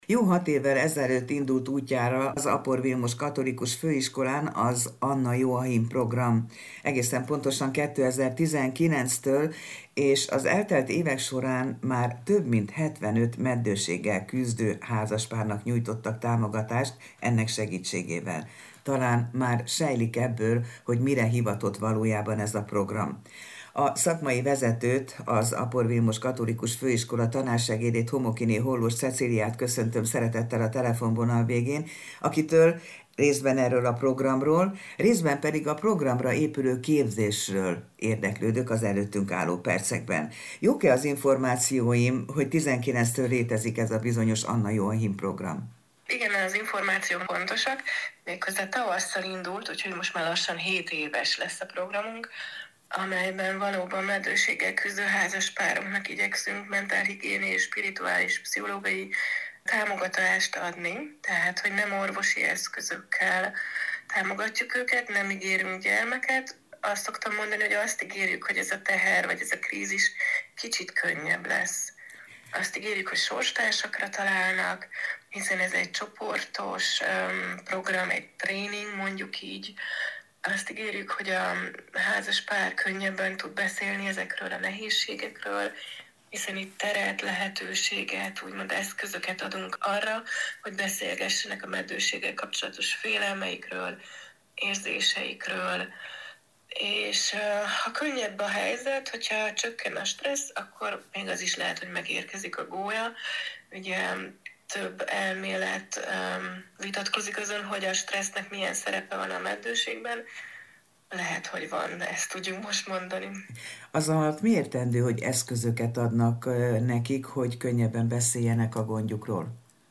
RÁDIÓ INTERJÚK, HANGANYAGOK